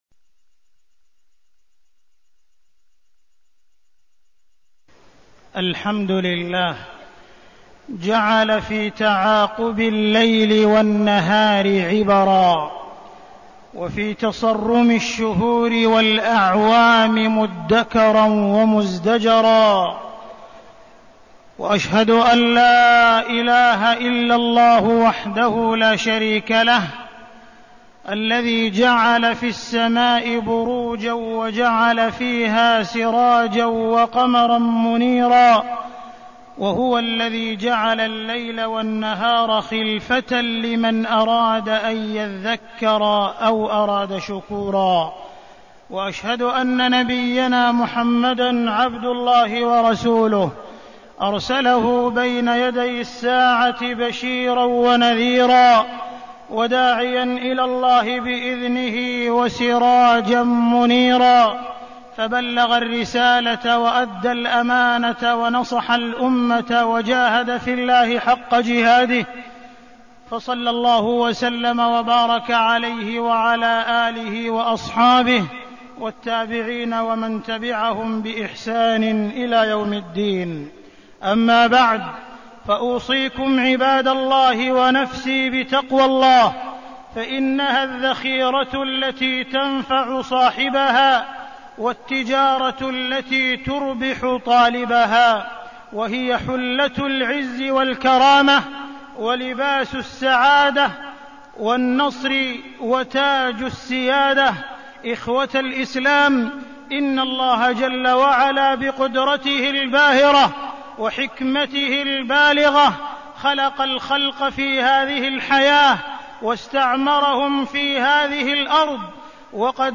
تاريخ النشر ٢٥ ذو الحجة ١٤١٧ هـ المكان: المسجد الحرام الشيخ: معالي الشيخ أ.د. عبدالرحمن بن عبدالعزيز السديس معالي الشيخ أ.د. عبدالرحمن بن عبدالعزيز السديس العام الهجري الجديد